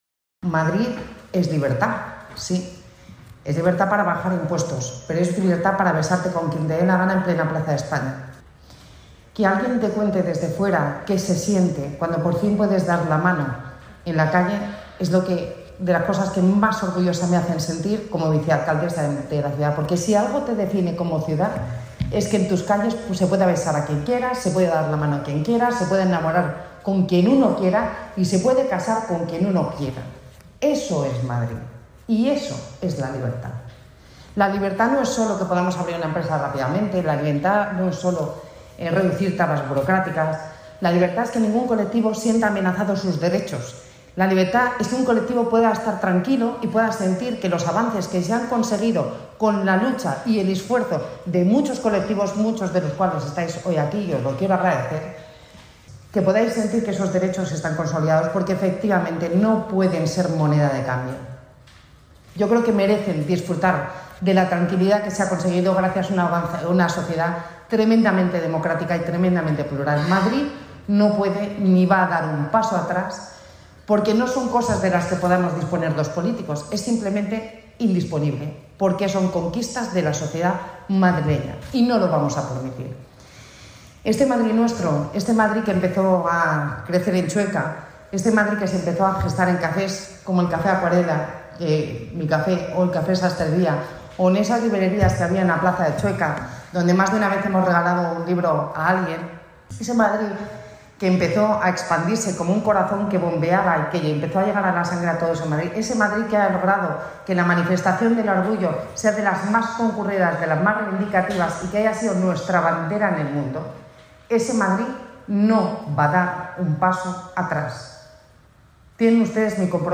Durante la presentación de la programación de Madrid Orgullo 2021
Nueva ventana:Begoña Villacís, vicealcaldesa de Madrid